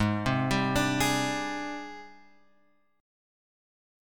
G# Major 7th Flat 5th